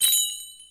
Chime.wav